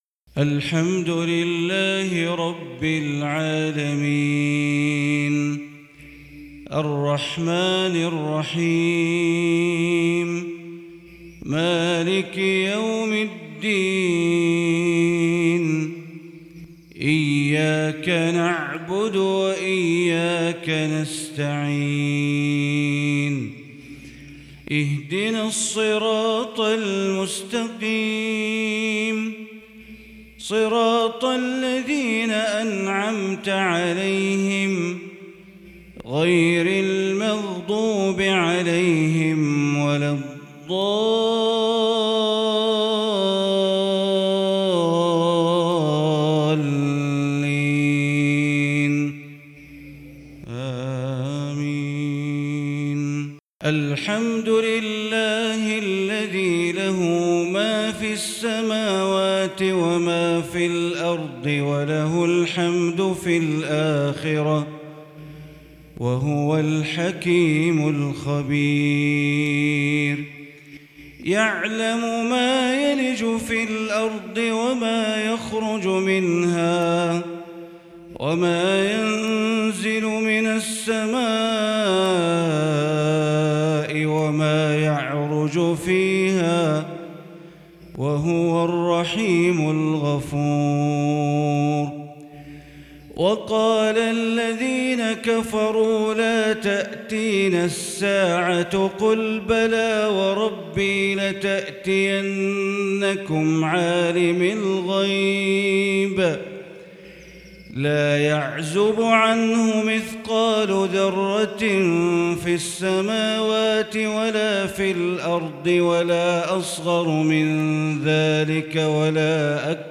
صلاة الفجر للشيخ بندر بليلة 2 رجب 1443هـ ۞ فواتح سورة سبأ 1 - 14